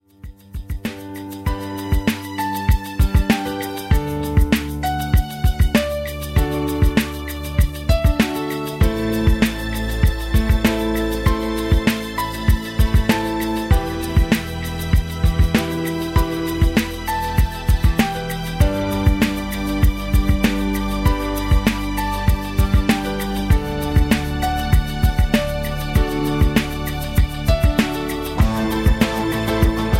Am
MPEG 1 Layer 3 (Stereo)
Backing track Karaoke
Pop, 1990s